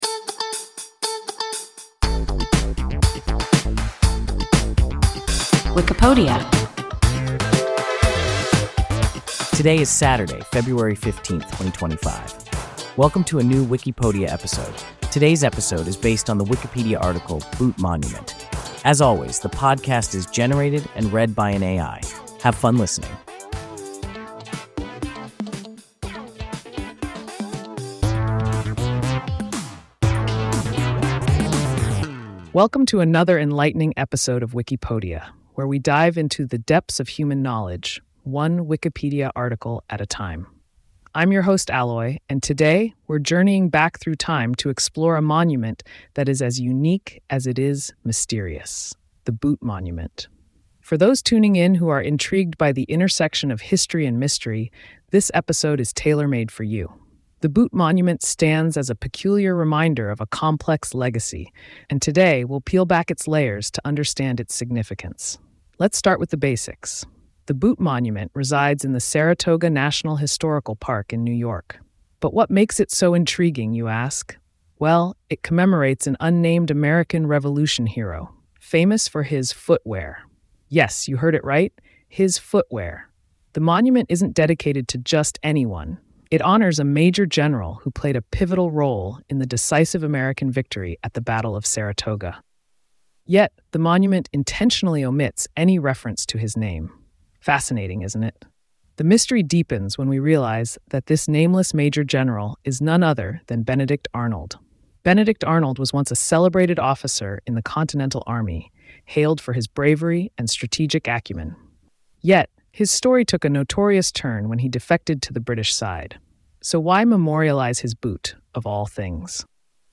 Boot Monument – WIKIPODIA – ein KI Podcast